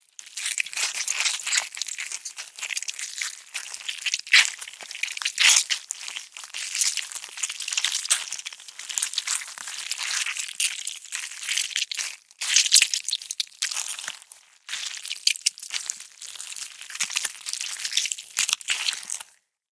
Звуки клея
Звук склеивания влаги